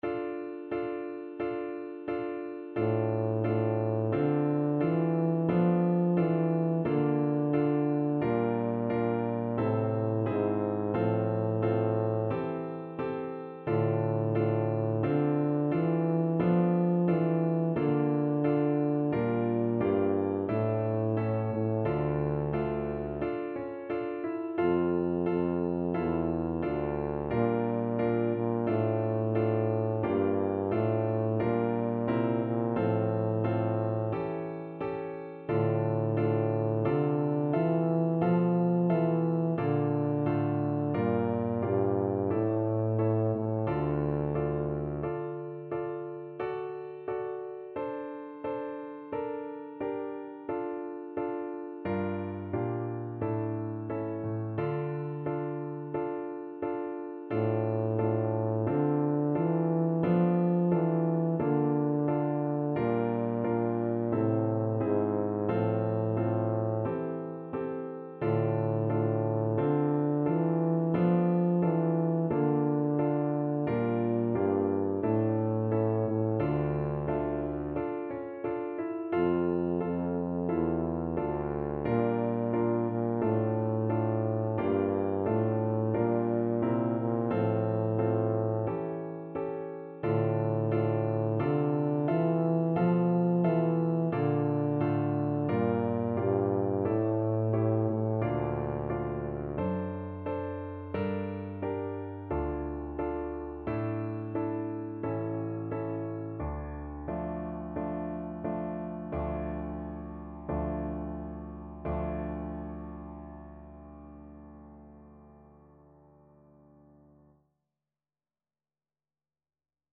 Tuba
D3-F4
D minor (Sounding Pitch) (View more D minor Music for Tuba )
4/4 (View more 4/4 Music)
Andante =c.88
Classical (View more Classical Tuba Music)